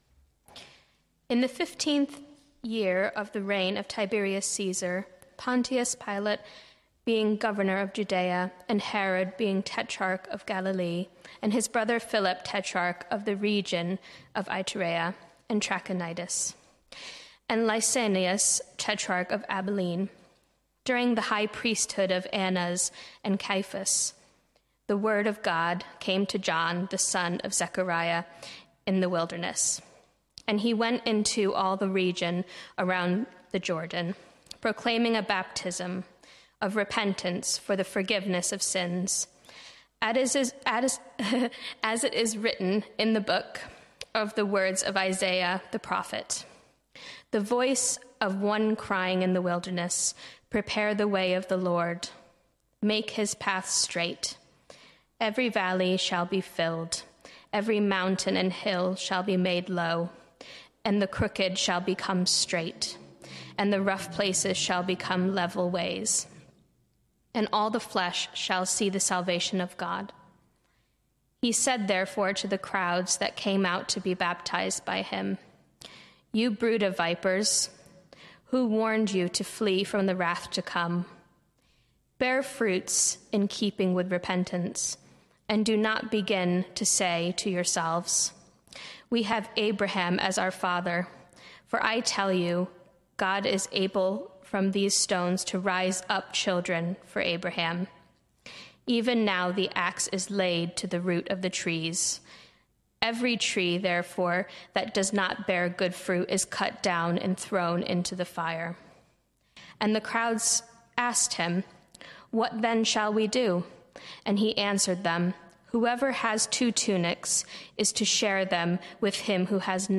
Sermon Series: Luke’s Gospel